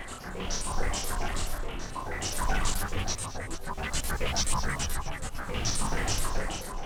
STK_MovingNoiseA-140_03.wav